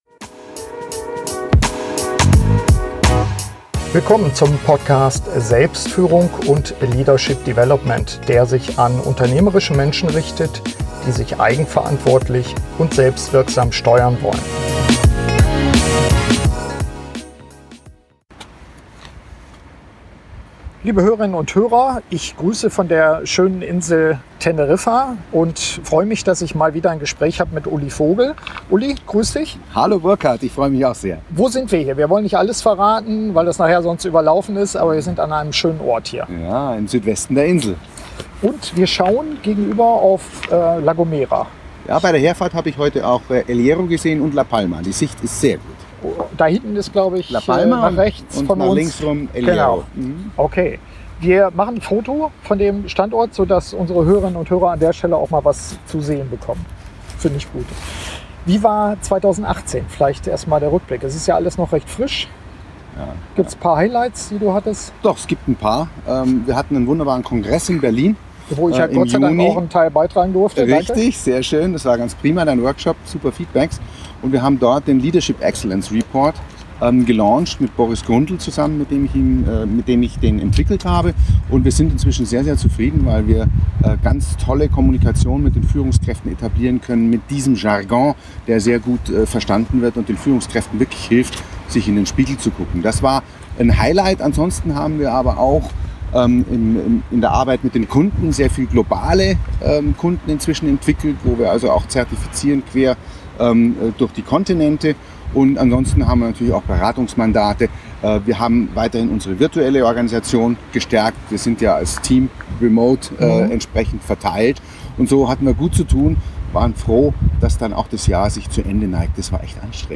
SF98 Wirksames Teambuilding - Gespräch